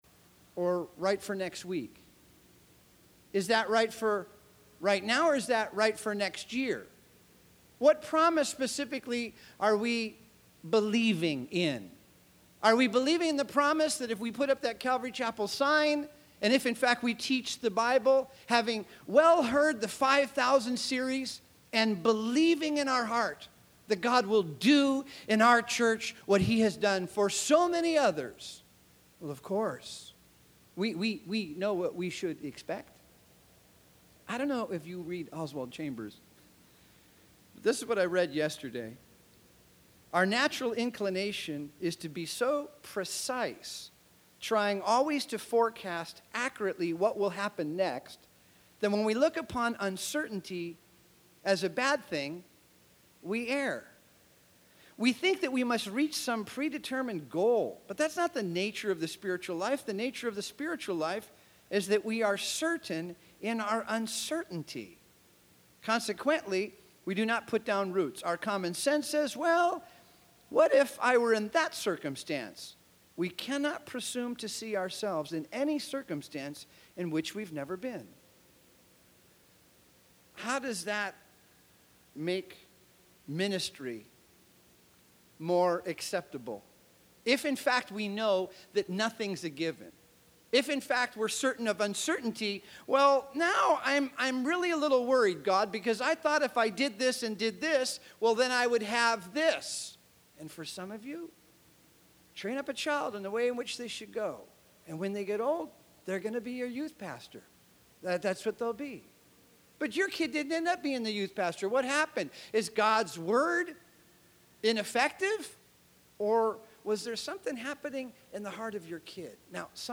2009 Home » Sermons » Session 6 Share Facebook Twitter LinkedIn Email Topics